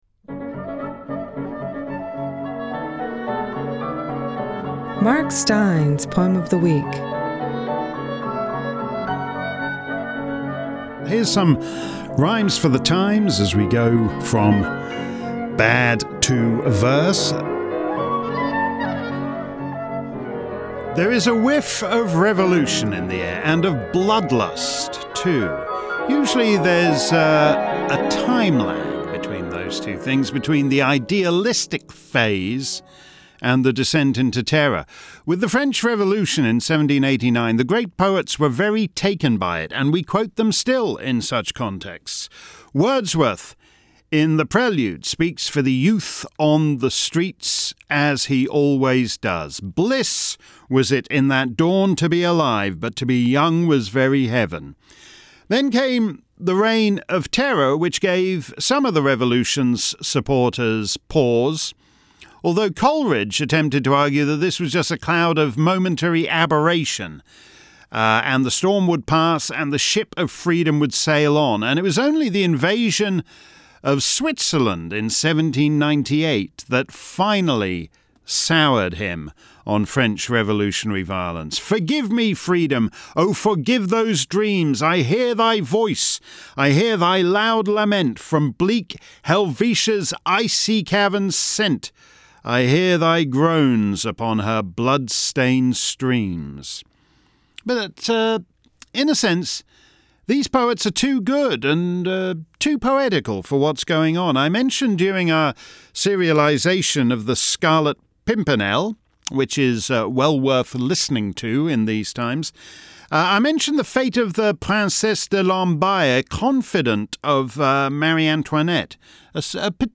Welcome to the second of our poetical specials this summer. With our video poetry shoots stymied by Covid, Mark's put together an anthology of some favorite Mark Steyn Show poems of recent months, and matched them to some appropriate musical interludes.